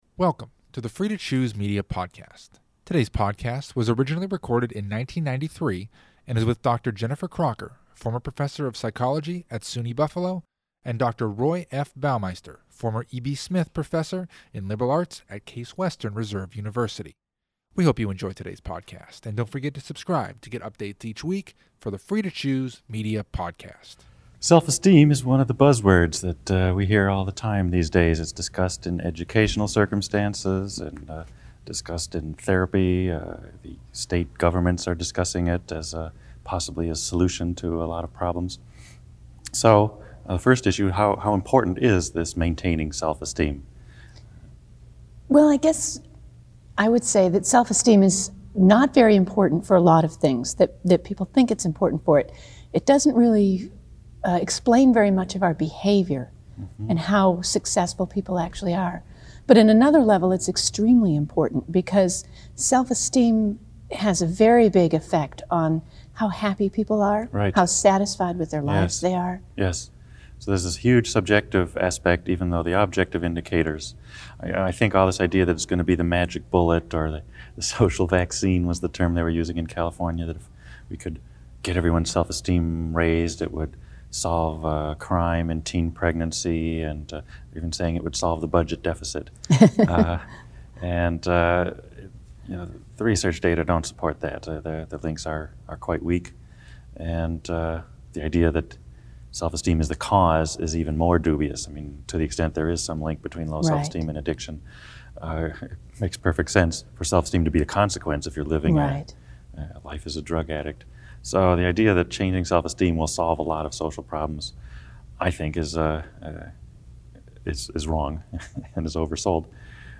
What is it like to live in a society which sends you the message that you're inferior? Listen to the 1993 discussion Maintaining Self-Esteem Against the Odds to find out.